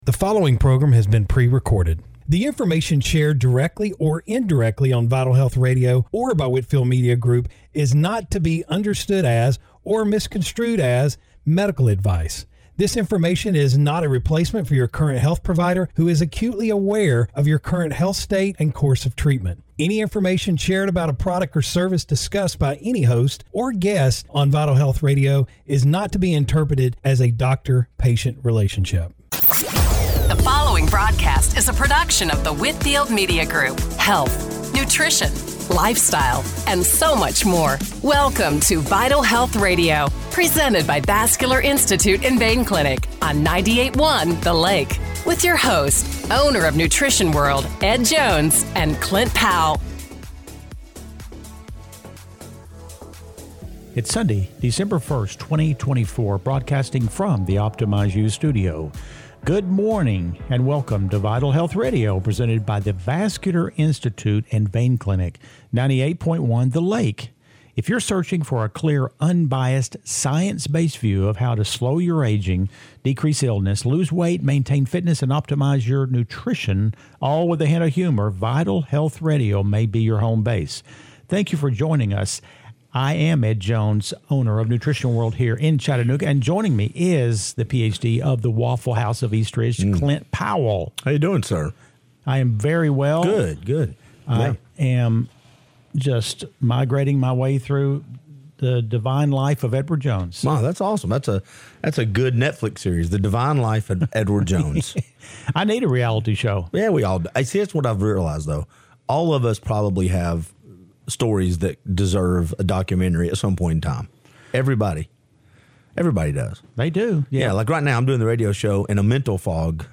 Radio Show – December 1, 2024 - Vital Health Radio